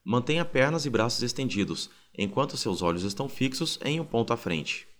I have two voice recordings… one that sounds good, and another that’s a bit muffled.